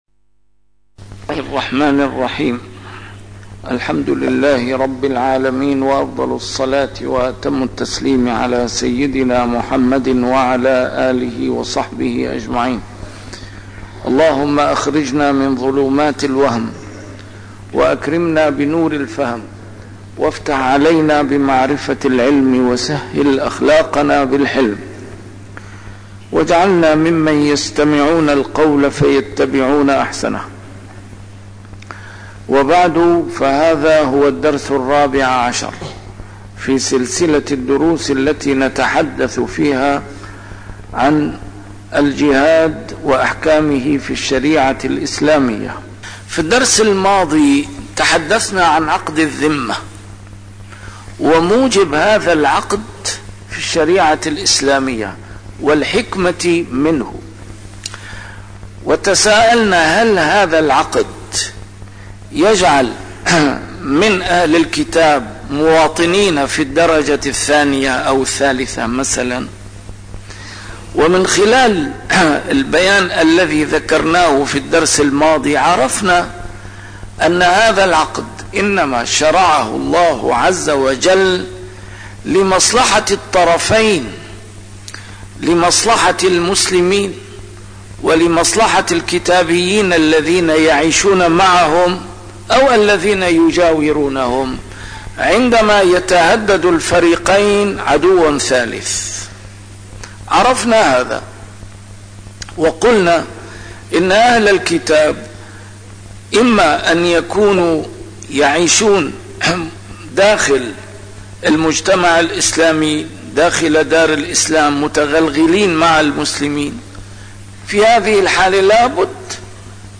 A MARTYR SCHOLAR: IMAM MUHAMMAD SAEED RAMADAN AL-BOUTI - الدروس العلمية - الجهاد في الإسلام - تسجيل قديم - الدرس الرابع عشر: الذمة وأحكامها
الجهاد في الإسلام - تسجيل قديم - A MARTYR SCHOLAR: IMAM MUHAMMAD SAEED RAMADAN AL-BOUTI - الدروس العلمية - فقه عام - الدرس الرابع عشر: الذمة وأحكامها